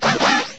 sovereignx/sound/direct_sound_samples/cries/sneasler.aif at master